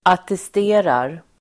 Uttal: [atest'e:rar]